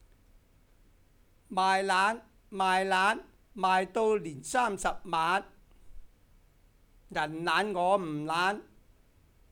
07卖懒卖懒94012童谣